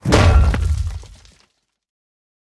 smash_ground2.ogg